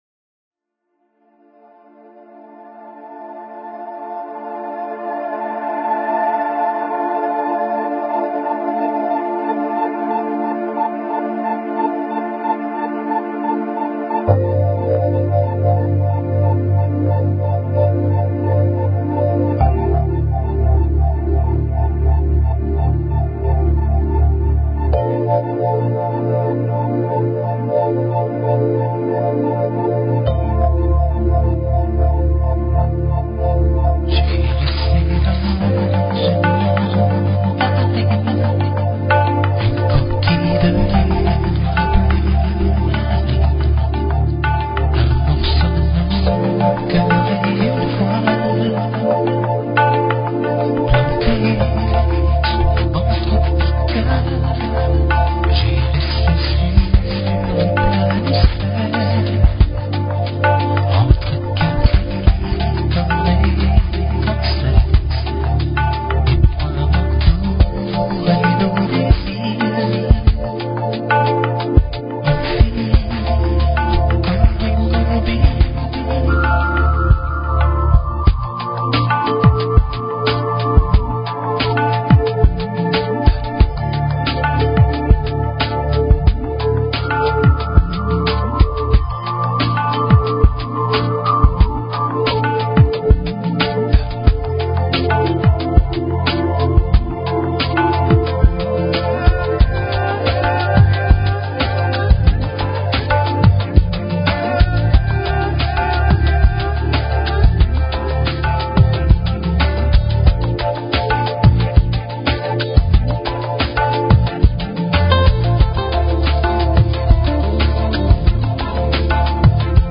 Guest, John Perkins